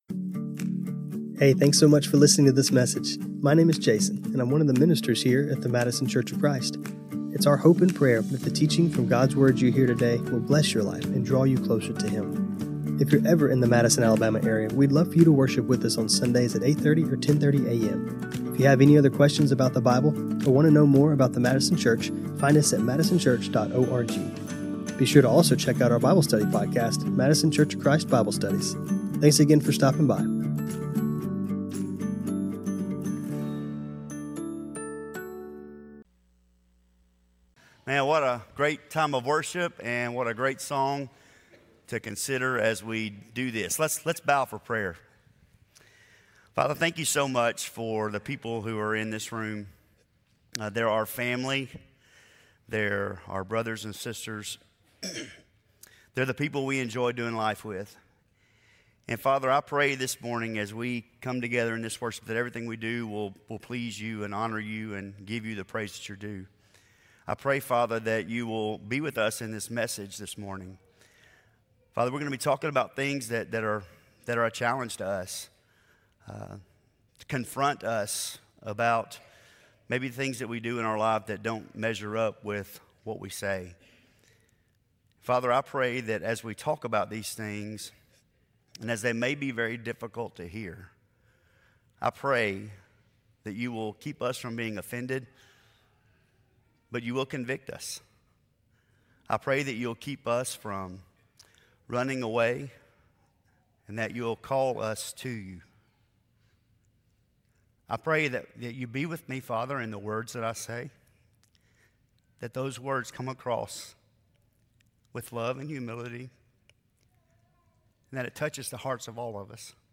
When our practices match our preaching, we are walking in the Way of Jesus. Scripture Reading: 1 Thessalonians 2:3-8 This sermon was recorded on Mar 22, 2026.